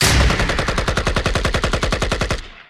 Auto Gun (1).wav